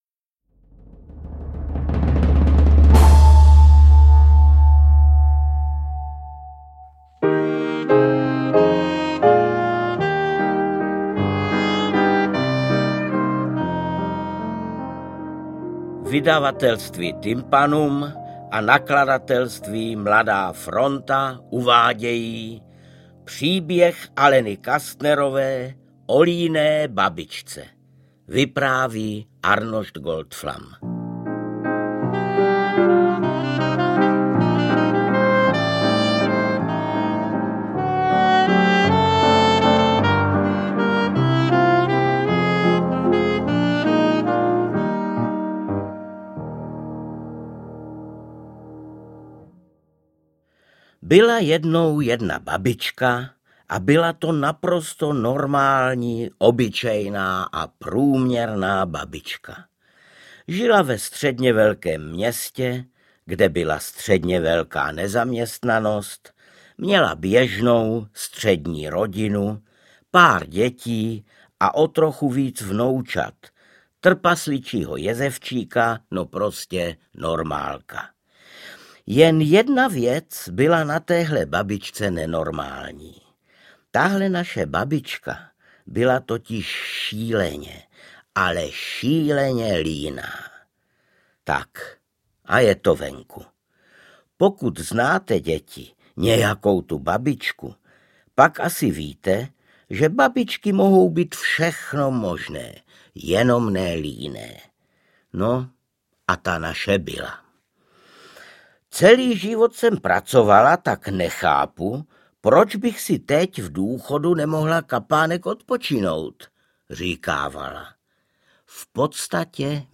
Interpret:  Arnošt Goldflam